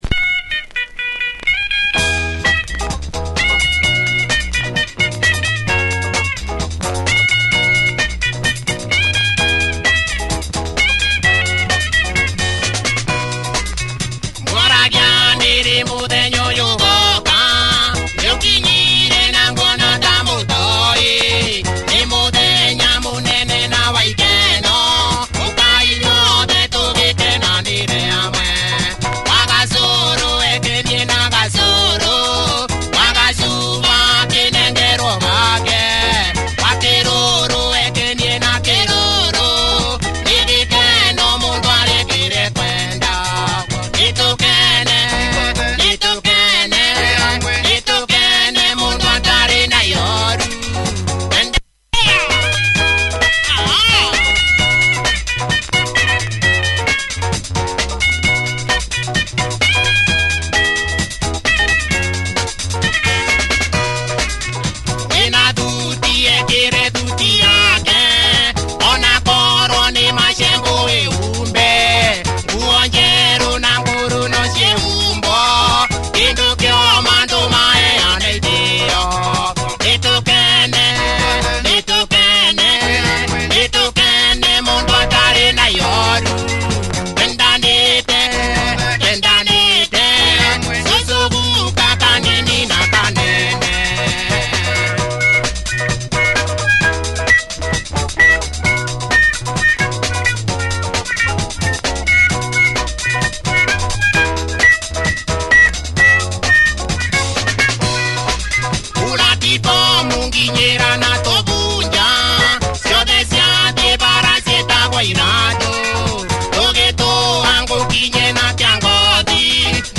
B-side plays first in audio.